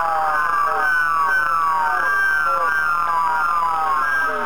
Типичные жуткие звуки